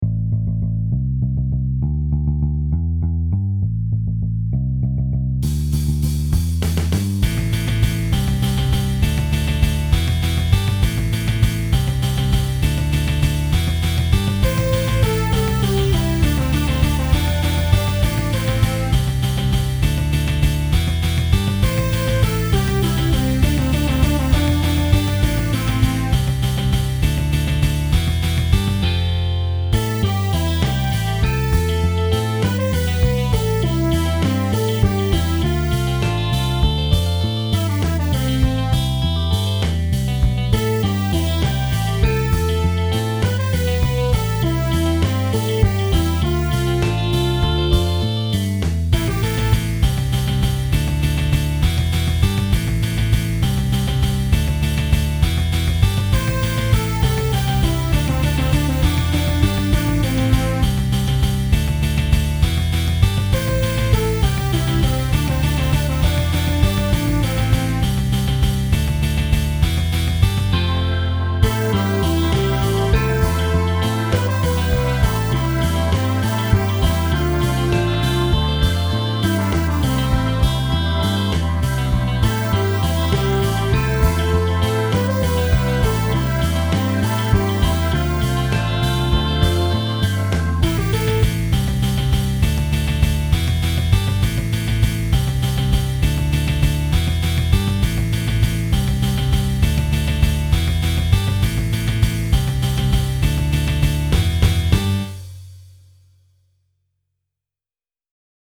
BluesRock
Synthesizer for the vocal part